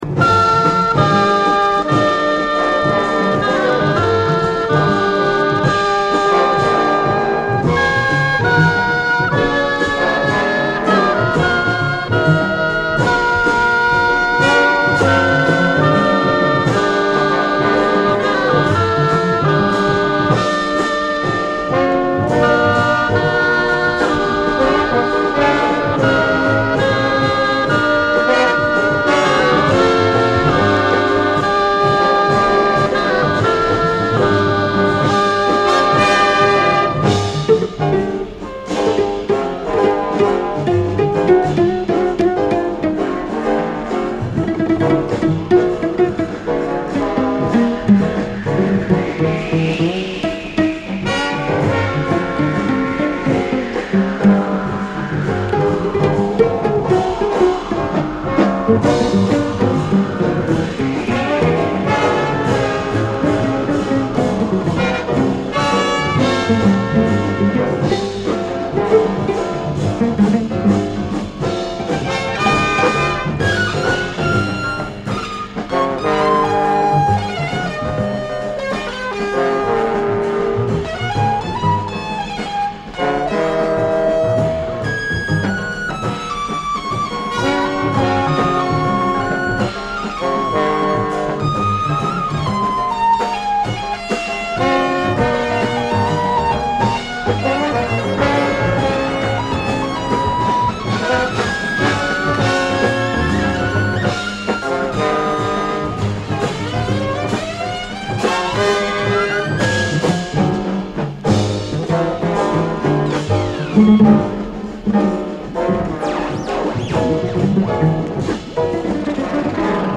rare groove anthem